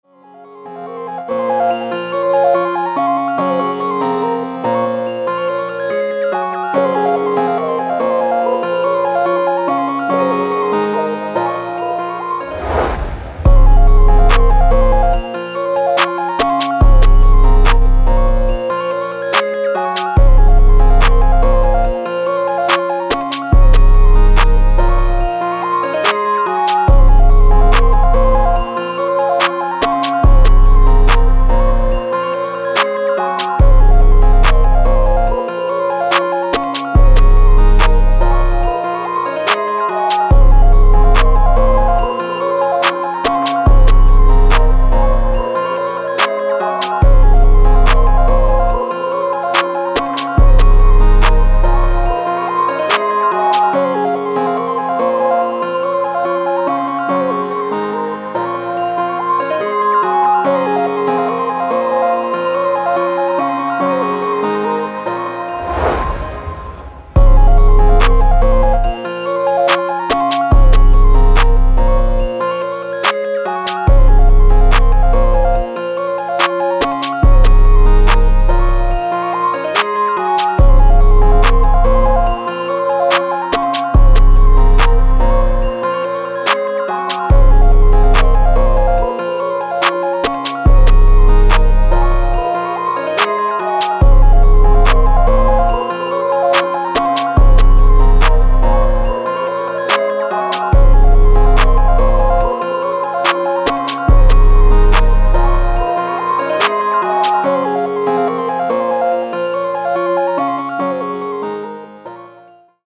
Cold, Dark, Energetic, Sexy
Drum, Heavy Bass, Piano, Strings